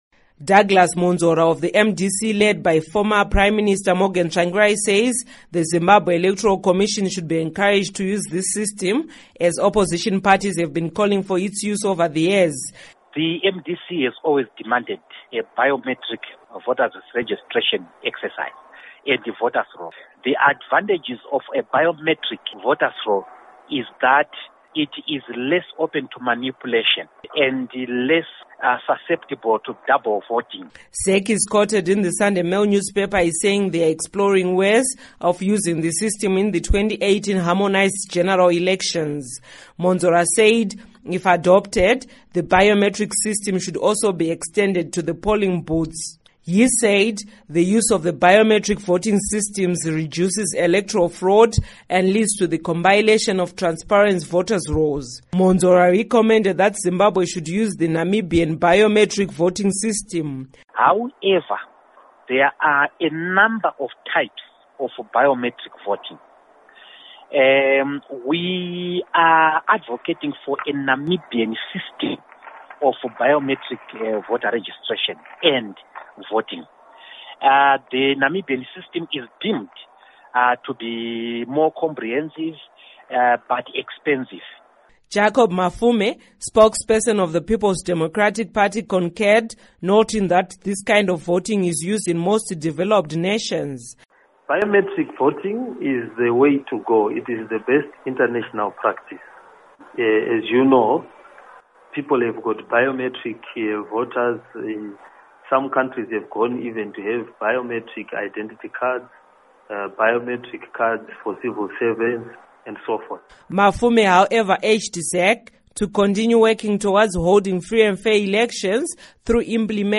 Report on Biometric Voting Systems